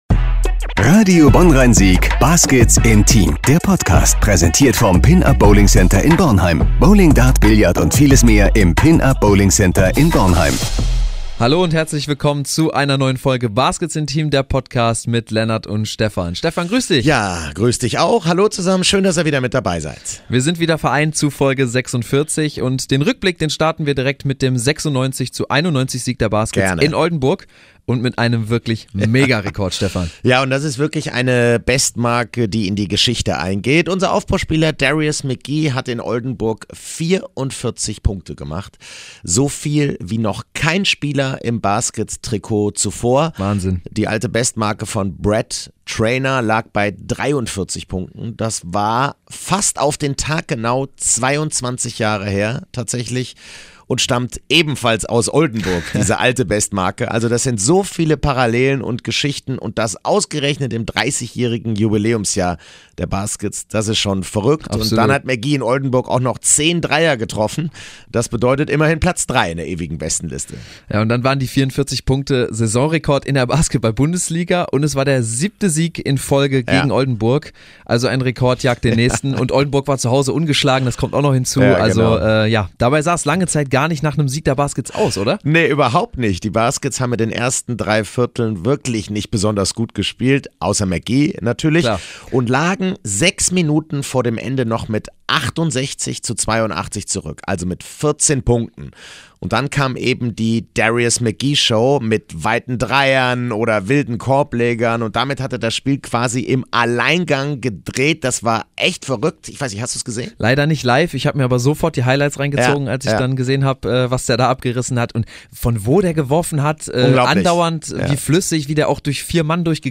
Mit dem Sieg gegen Riga haben sich die Baskets eine perfekte Ausgangsposition geschaffen, um die nächste Runde in der Champions-League zu erreichen. Noch mehr Stimmen und Stimmungen zu den Siegen gegen Oldenburg und Riga und einen Ausblick auf das Spiel in Bamberg, hört ihr in der aktuellen Episode.